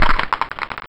made bone death sound louder
die_skeleton.wav